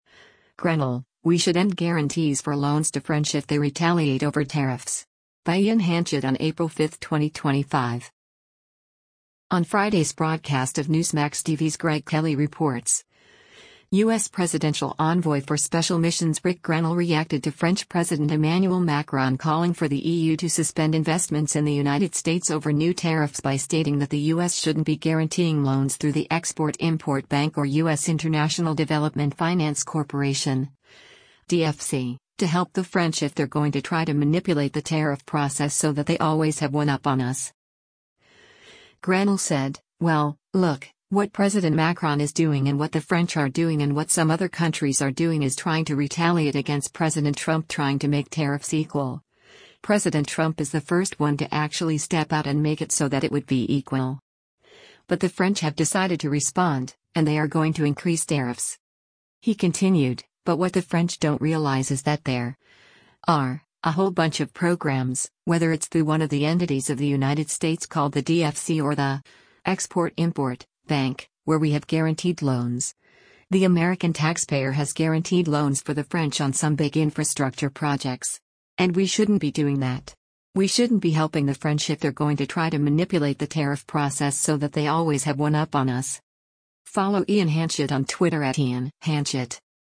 On Friday’s broadcast of Newsmax TV’s “Greg Kelly Reports,” U.S. Presidential Envoy for Special Missions Ric Grenell reacted to French President Emmanuel Macron calling for the E.U. to suspend investments in the United States over new tariffs by stating that the U.S. shouldn’t be guaranteeing loans through the Export-Import Bank or U.S. International Development Finance Corporation (DFC) to help the French “if they’re going to try to manipulate the tariff process so that they always have one up on us.”